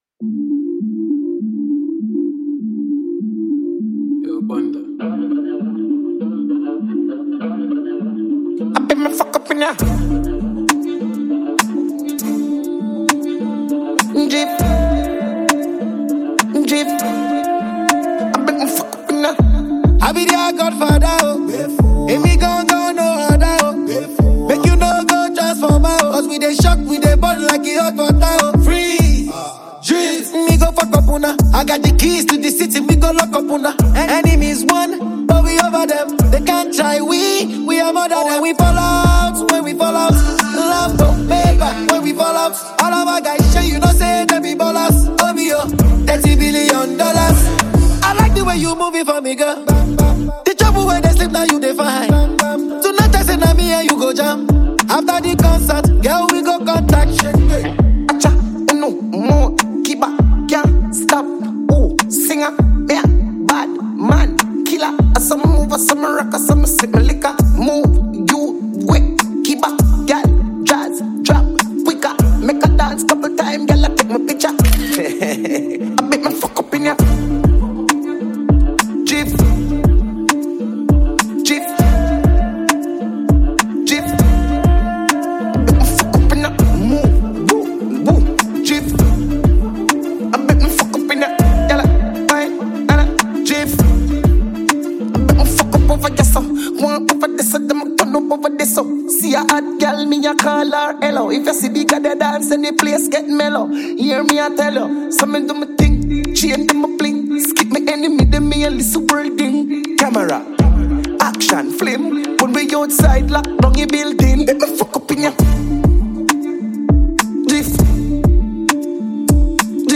gbedu